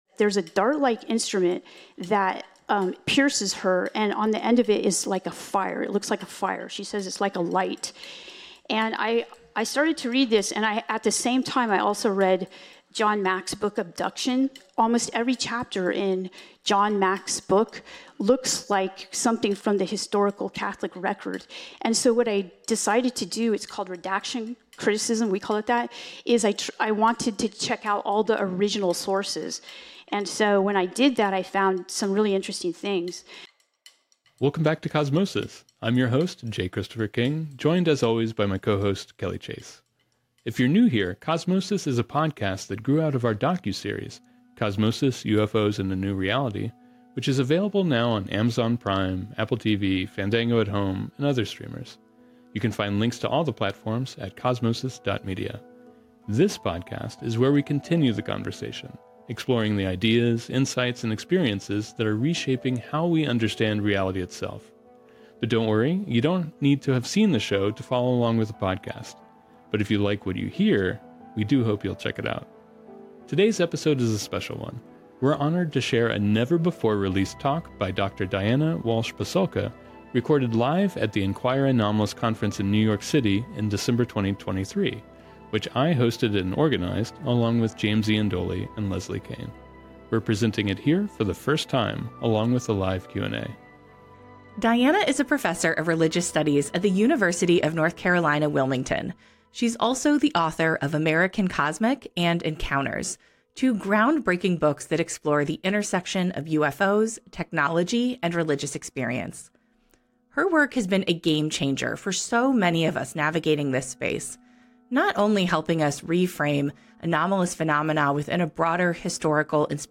Social Sciences, Society & Culture, Science, Documentary